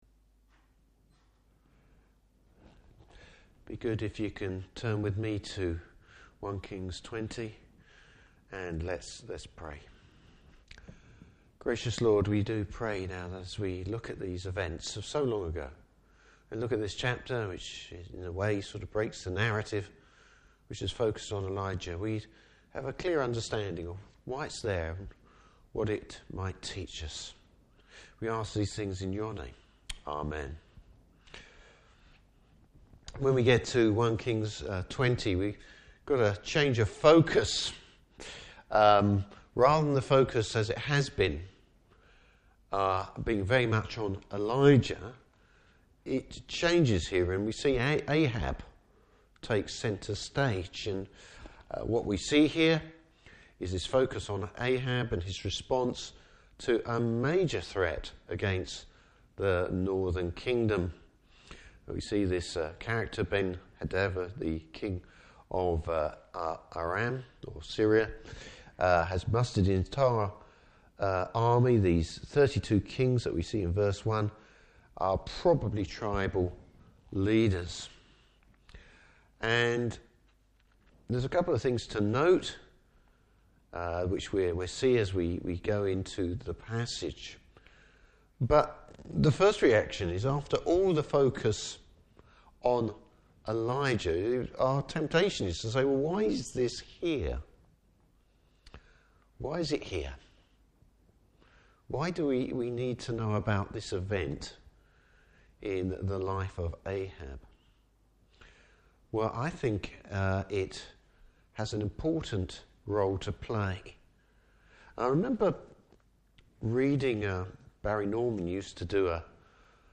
Service Type: Evening Service Bible Text: 1 Kings 20.